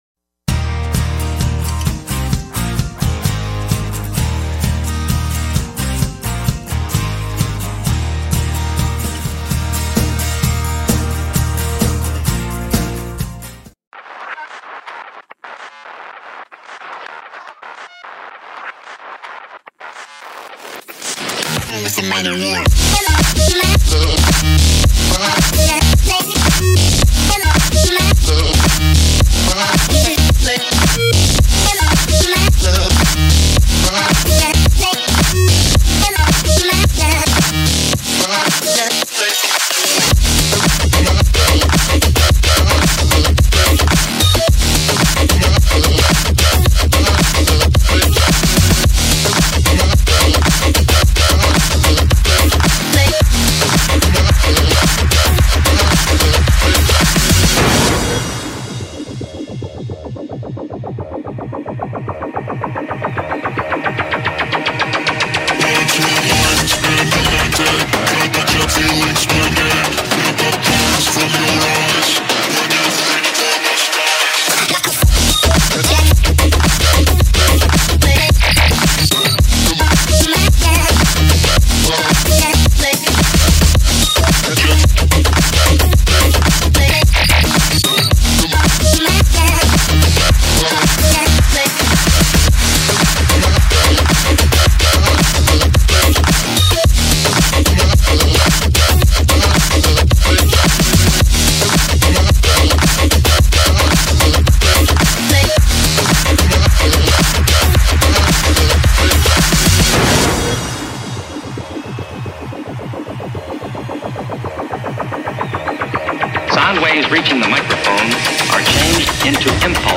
no copyright motivetional background gaming music